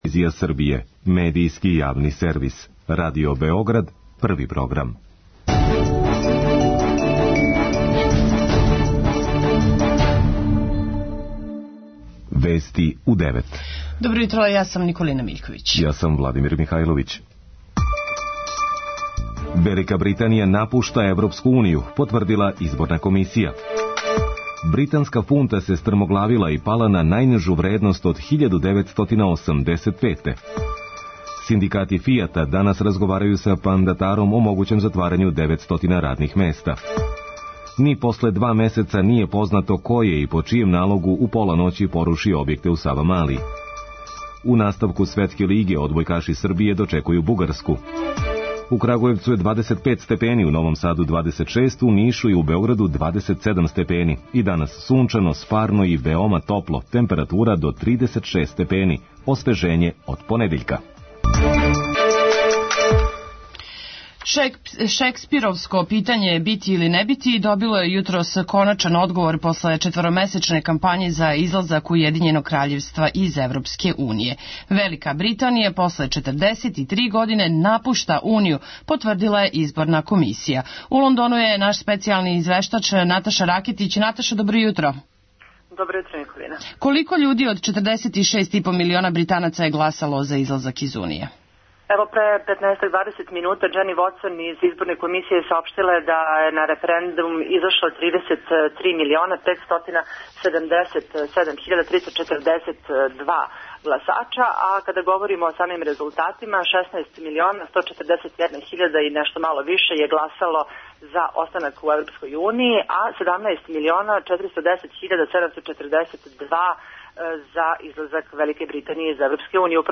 преузми : 4.02 MB Вести у 9 Autor: разни аутори Преглед најважнијиx информација из земље из света.